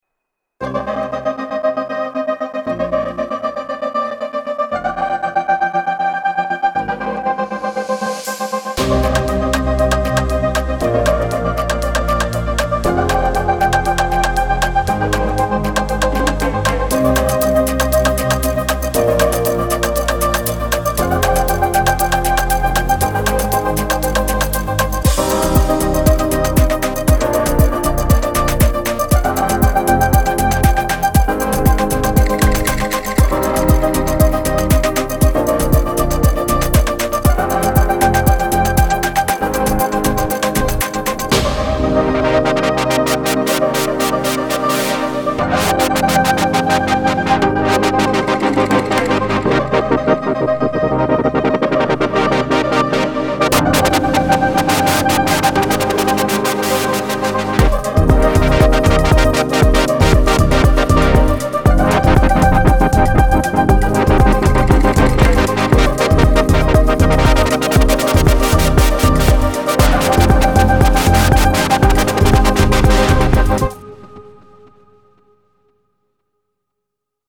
מקצביים שבנתי על אורגן